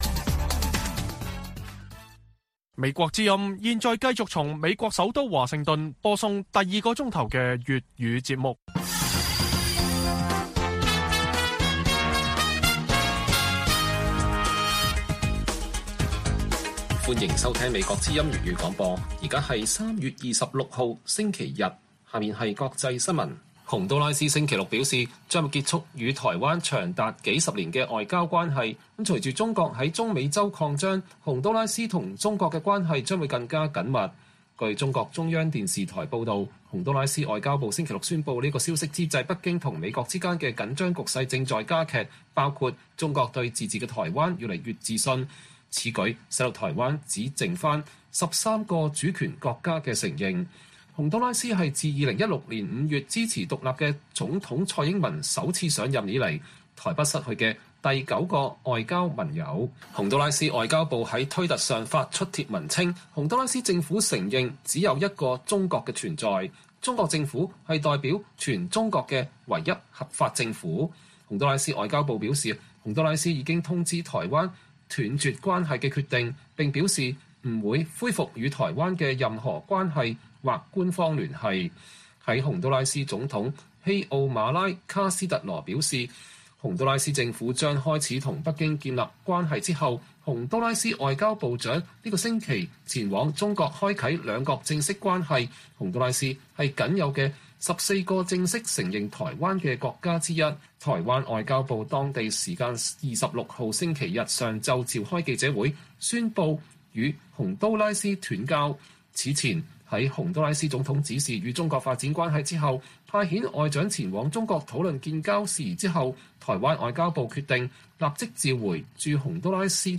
粵語新聞 晚上10-11點 : 世界媒體看中國：習近平的普京情